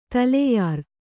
♪ taleyār